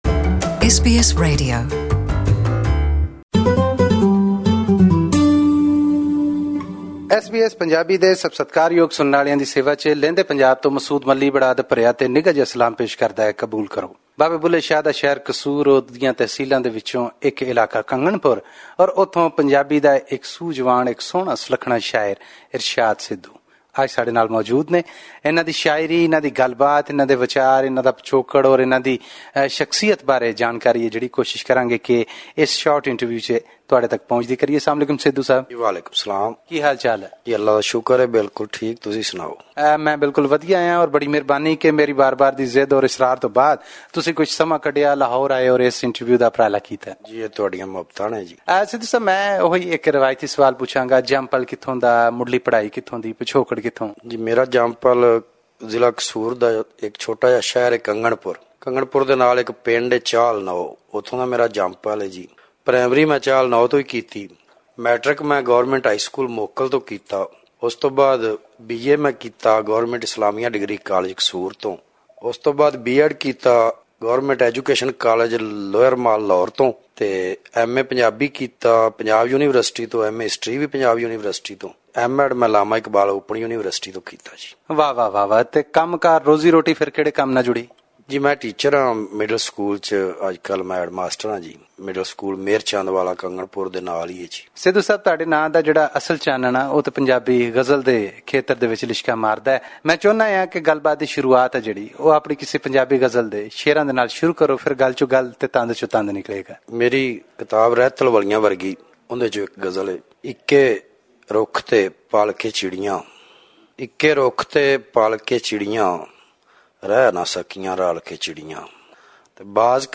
On World Teacher’s Day, SBS Punjabi interviewed this Pakistani Punjabi poet who is also a teacher.